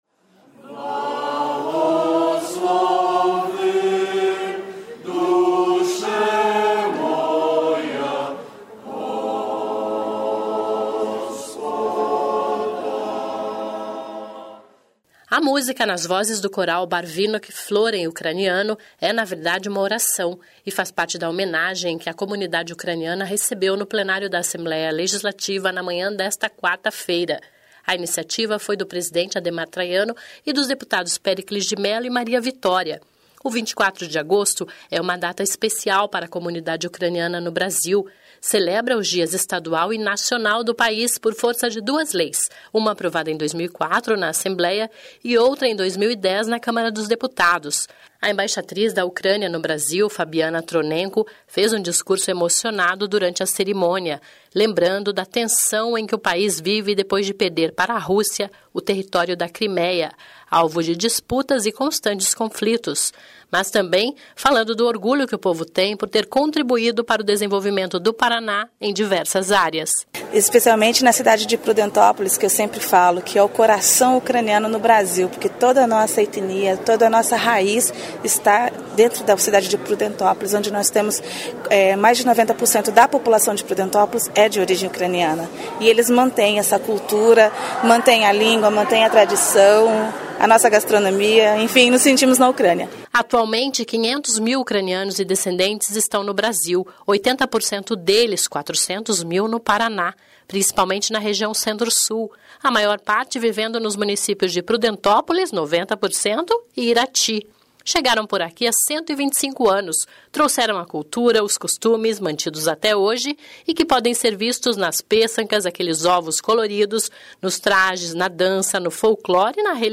(Começa com sobe som)